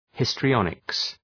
Προφορά
{,hıstrı’ɒnıks} (Ουσιαστικό) ● θεατρισμός